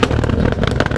ExhaustFire2.wav